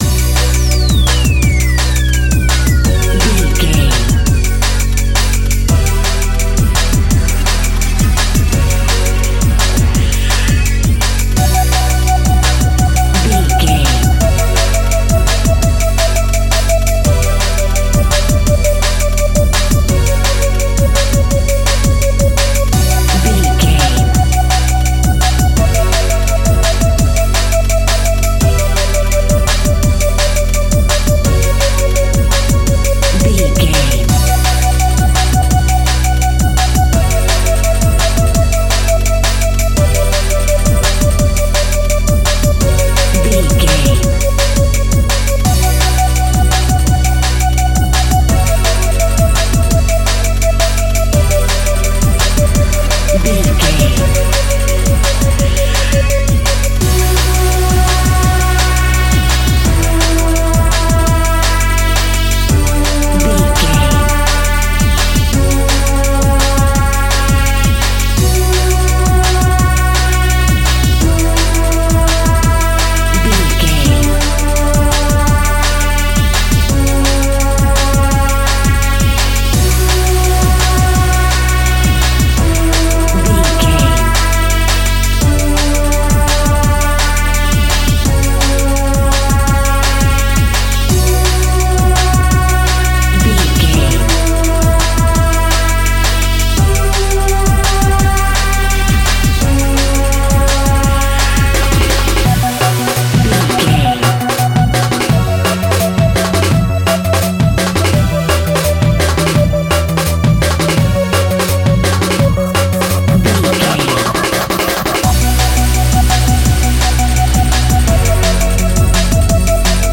Aeolian/Minor
D
Fast
groovy
dark
futuristic
energetic
frantic
driving
synthesiser
drum machine
electronic
synth bass
synth lead
synth pad
robotic